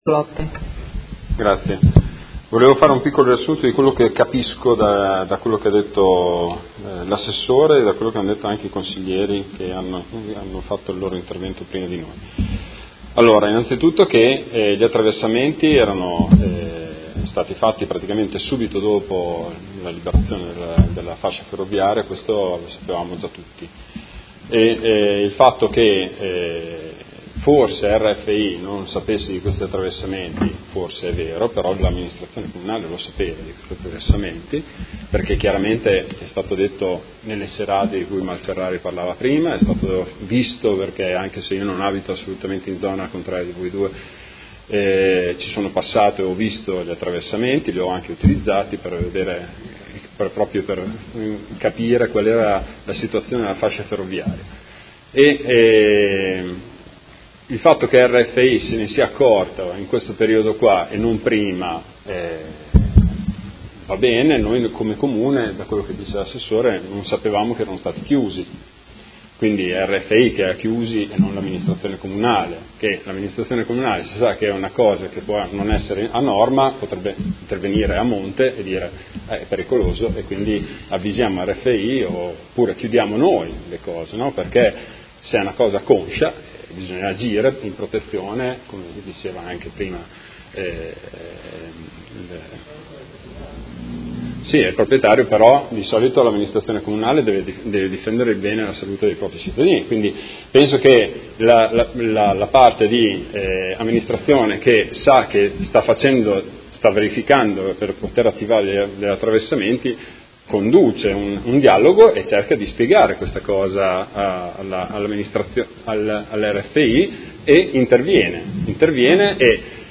Seduta del 8/6/2017 Interrogazione del Gruppo Movimento cinque Stelle avente per oggetto: Chiusura dei sentieri spontanei lungo la massicciata dell’ex ferrovia in zona Villaggio Artigiano Modena Ovest. Trasformata in interpellanza su richiesta del Consigliere Cugusi